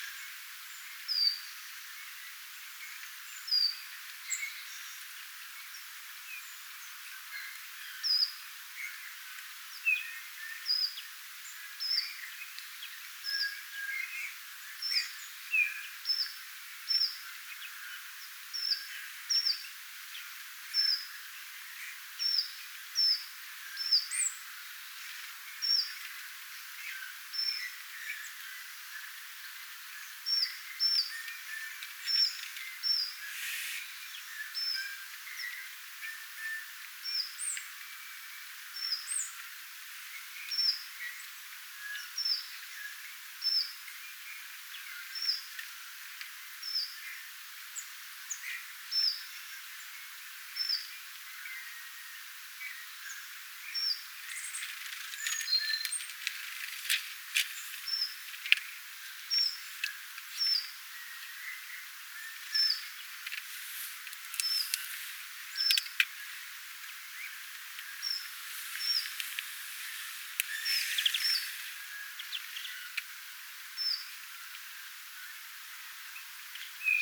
kuusitiaien säälittävää vihellystä
Surumielisiä ääniä?
kuusitiainen_viheltelee_saalia_herattavasti.mp3